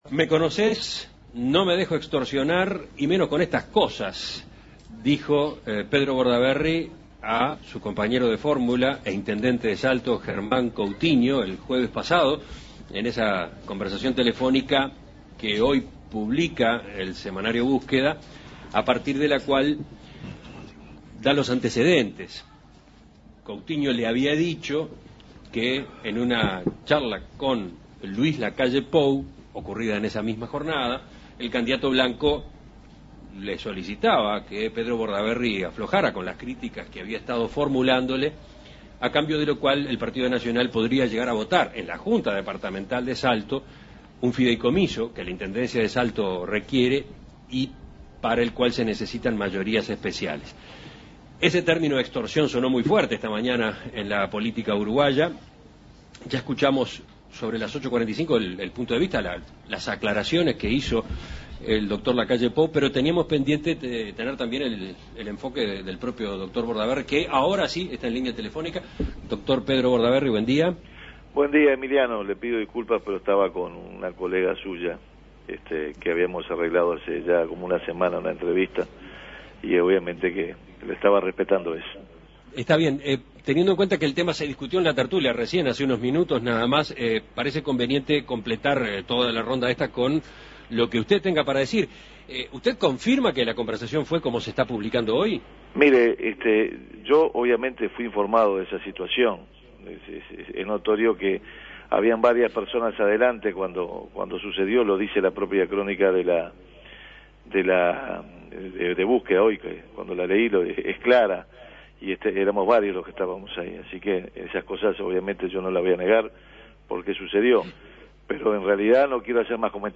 En diálogo con En Perspectiva, el candidato colorado a la Presidencia le bajó el tono a la polémica y lo calificó de tema menor.